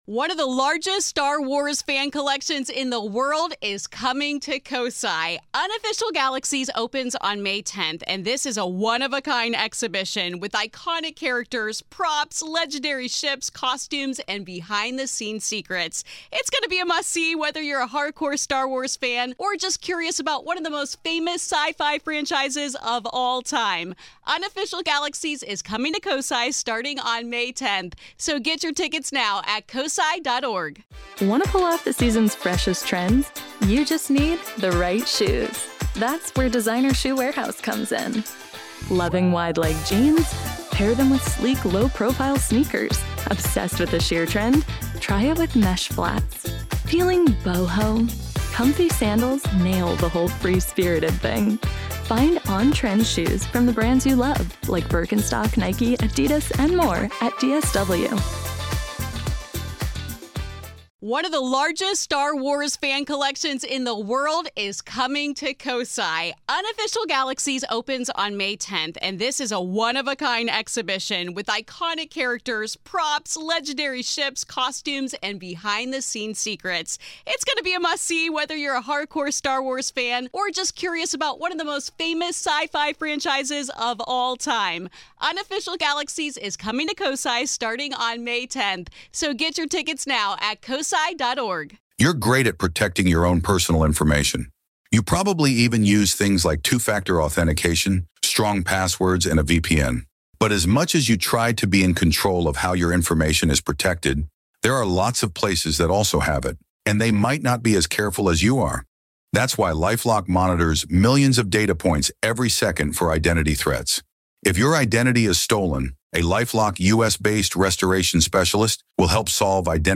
a conversation about the history and the hauntings of the Old Idado Penitentiary